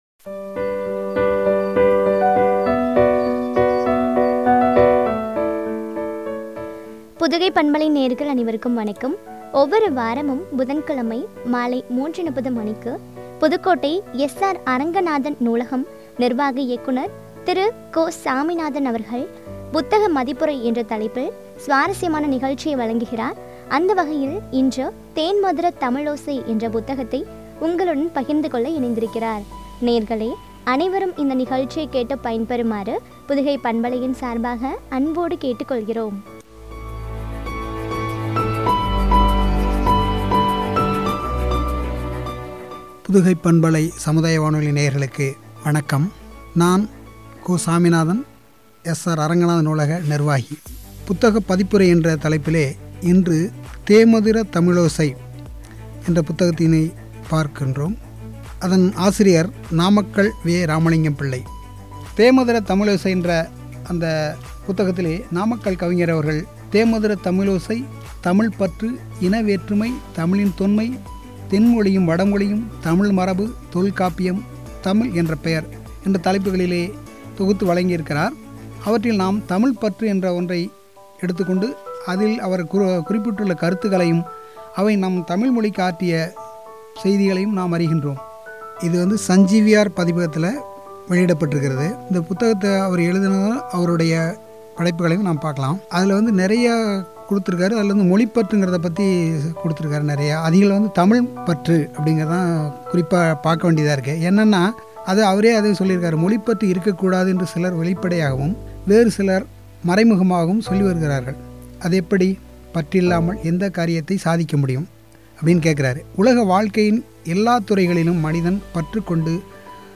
“தேன்மதுர தமிழோசை ” புத்தக மதிப்புரை (பகுதி -02) குறித்த வழங்கிய உரையாடல்.